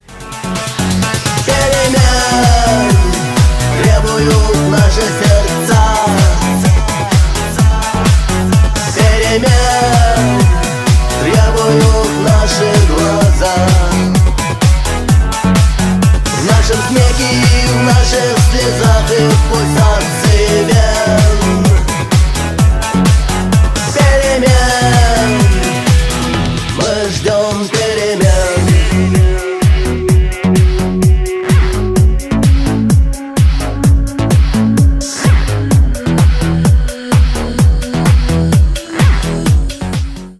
Ремикс # Танцевальные
громкие